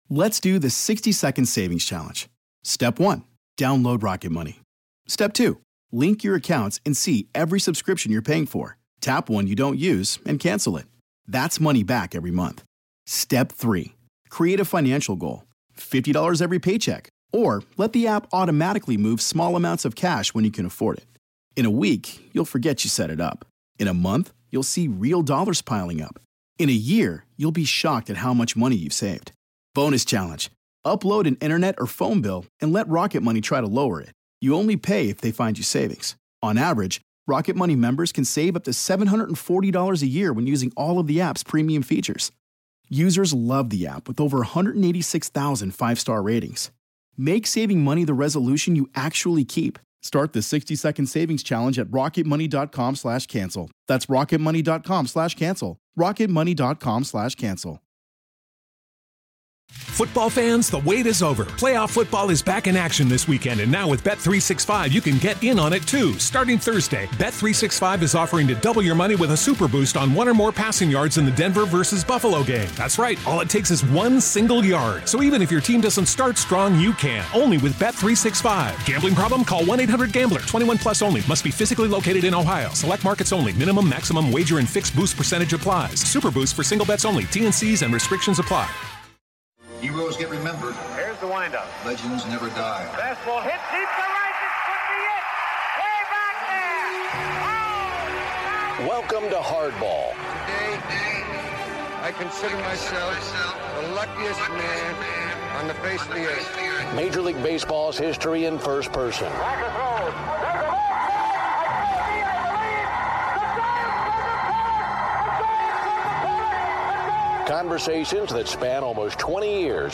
It's with a heavy heart that I present my conversation with Brooks Robinson.